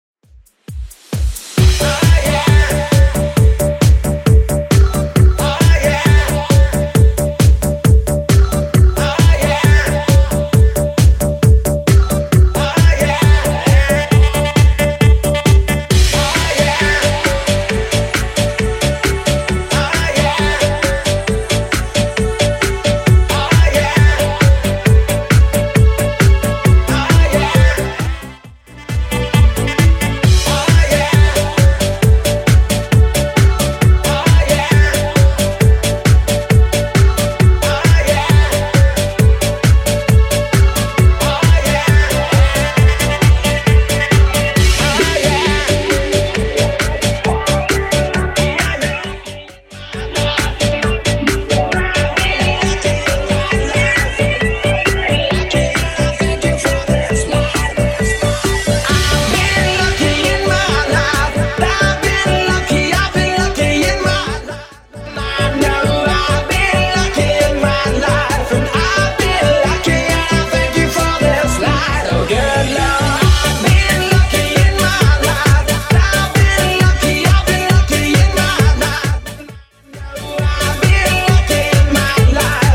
Genre: 90's Version: Clean BPM: 98